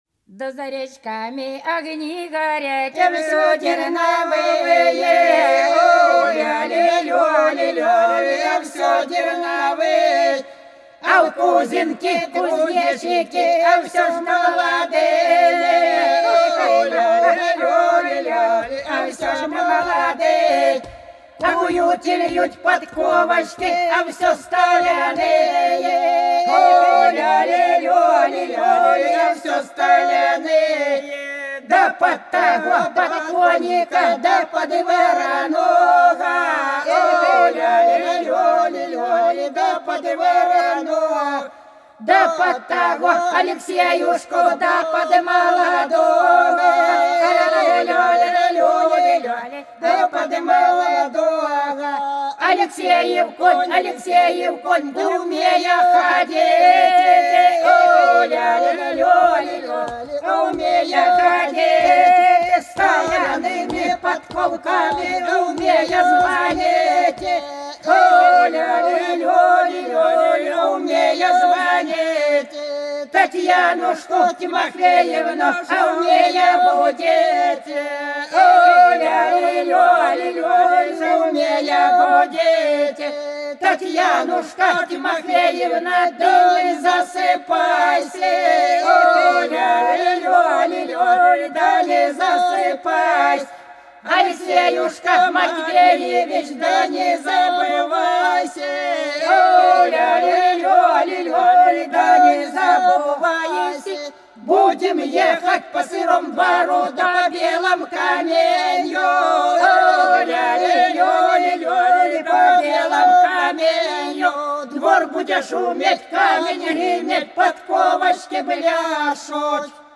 По-над садом, садом дорожка лежала Да за речками огни горят да всё терновые - свадебная (с.Плёхово, Курская область)